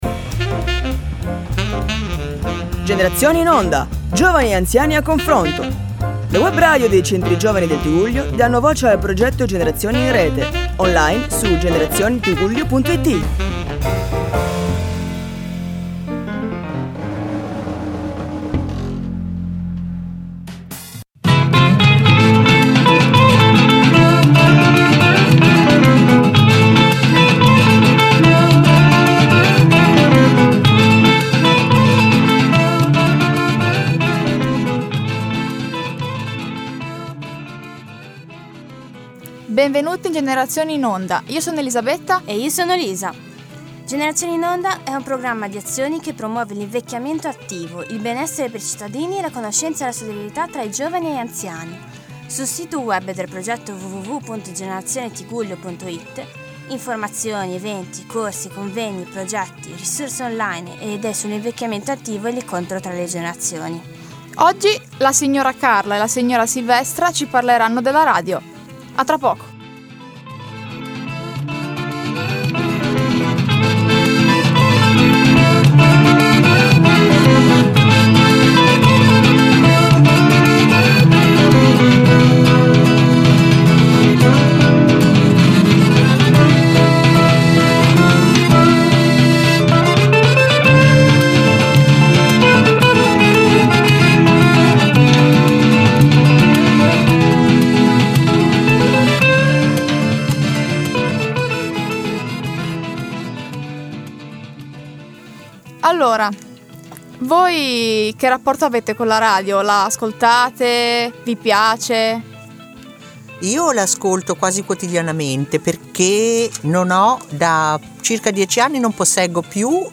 un interessante incontro/scambio generazionale tra i ragazzi della nostra redazione e alcune persone più adulte. Il tema portante è la radio: Costruiamo così un interessante percorso della memoria e dello scambio intergenerazionale attorno al tema della radio e del rapporto con le tecnologie in generale ed i media.